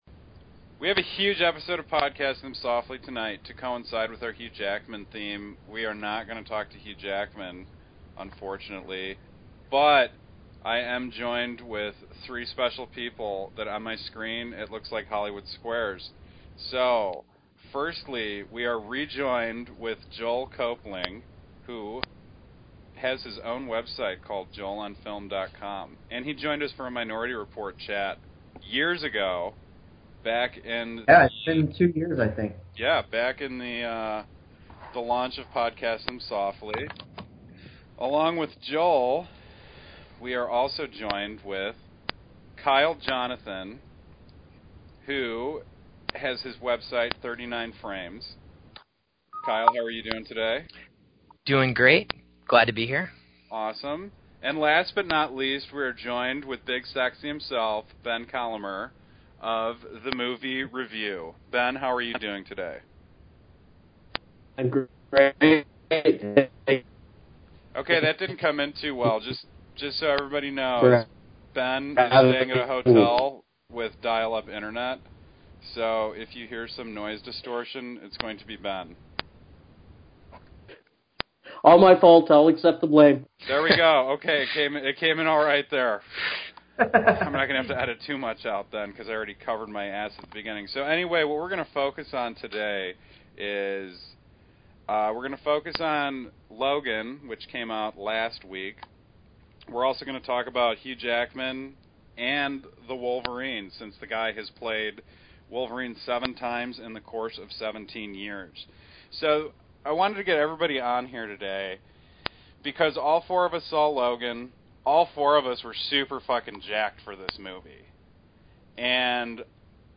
Space Operatic: An Interview